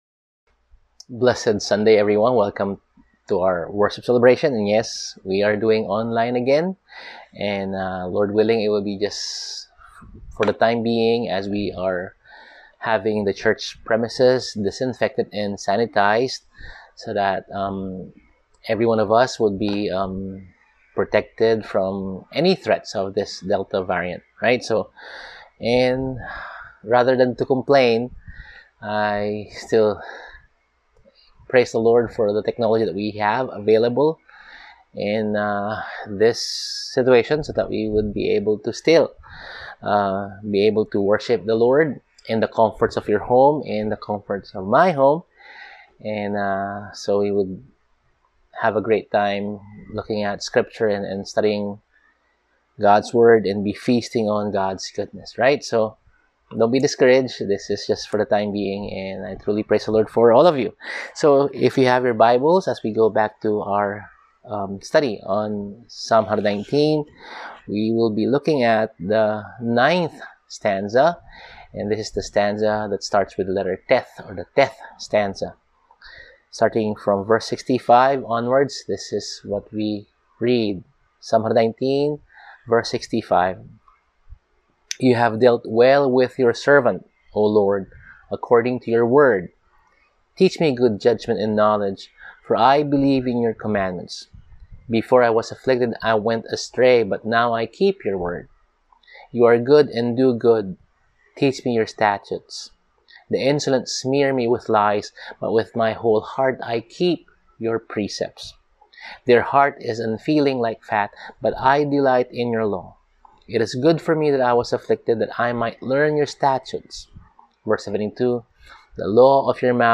Service: Sunday Sermon